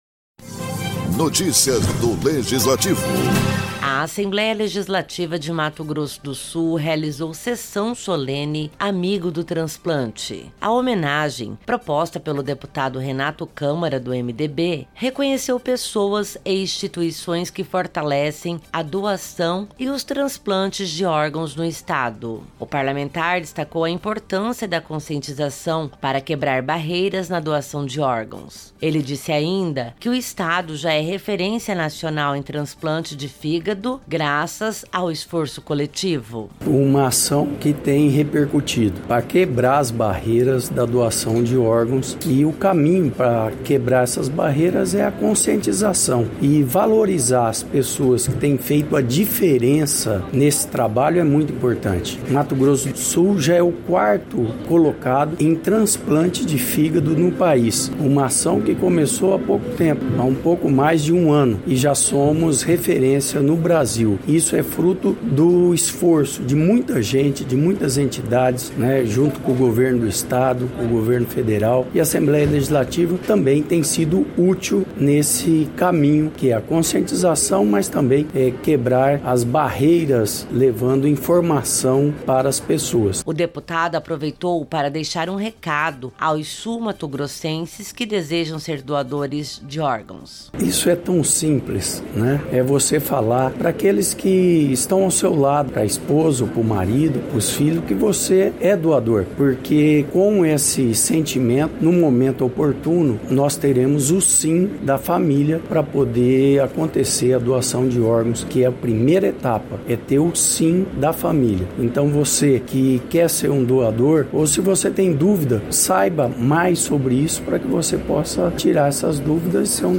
Em uma noite marcada por reconhecimento e emoção, a Assembleia Legislativa de Mato Grosso do Sul, realizou Sessão Solene de Entrega do Diploma de Honra ao Mérito Legislativo “Amigo do Transplante”, instituído pela Resolução 32/2019 (página 2).